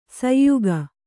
♪ sayyuga